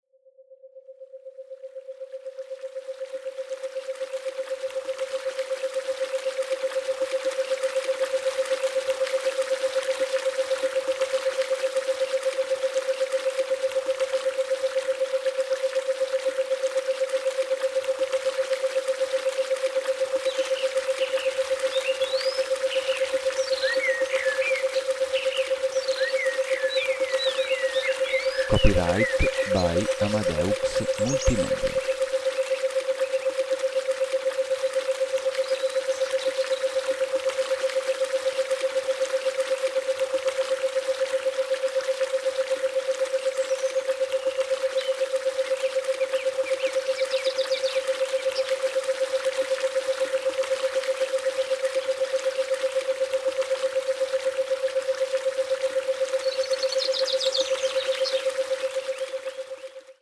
9.AT005-ruscello-528hbx-bwe8hz-demo